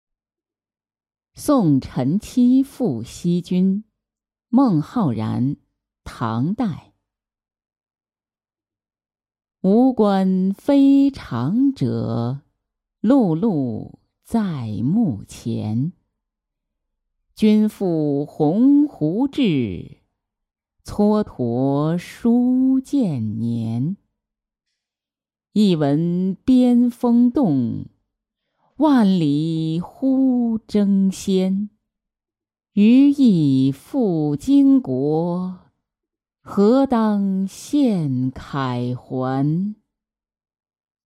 送陈七赴西军-音频朗读